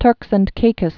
(tûrks)